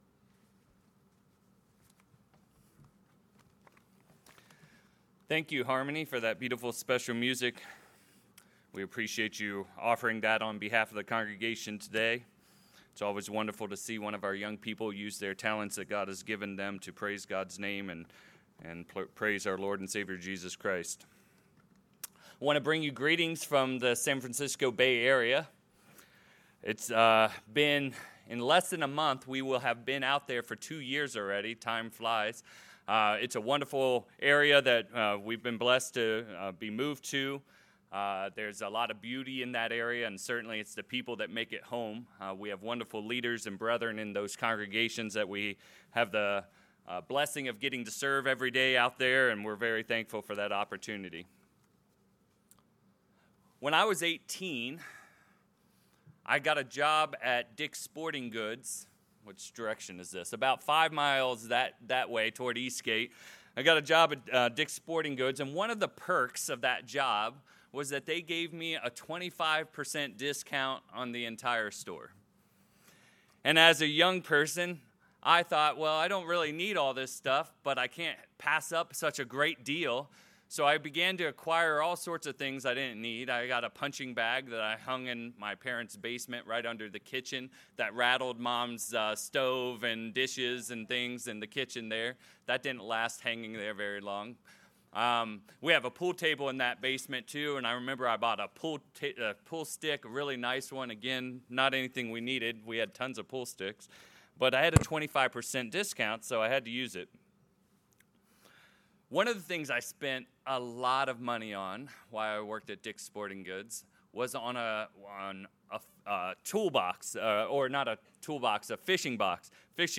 Satan uses similar methods to tempt Christians to sin. In this sermon, we’ll discuss six similarities to these methods and dive into the temptation process that the Apostle James outlines.